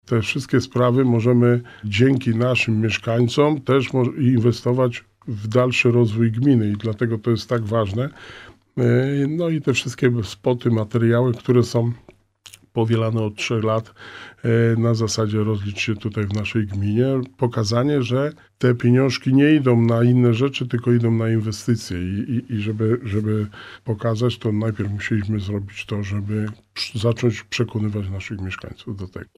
Pozyskane w ten sposób środki przyczyniają się do rozwoju infrastruktury, podniesienia komfortu życia mieszkańców i jakości usług publicznych, dodaje wójt gminy Długołęka.